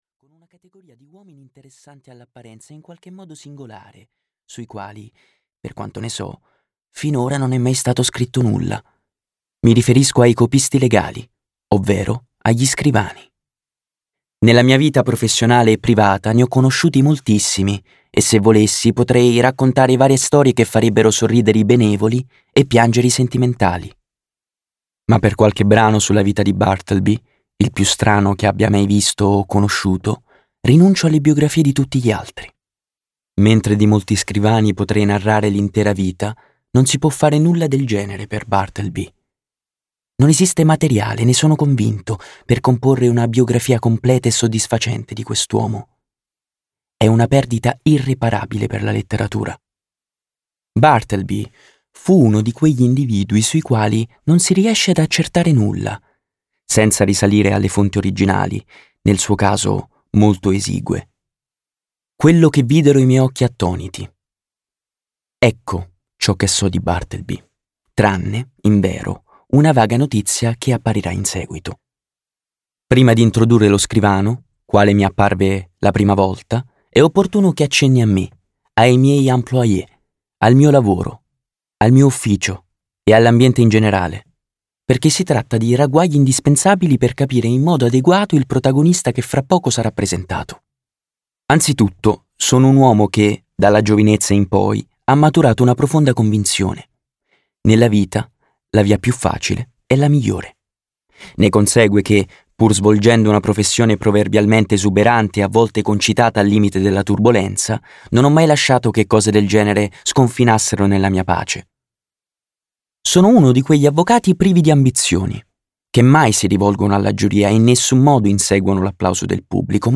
Letto da: Filippo Scotti
Audiolibro digitale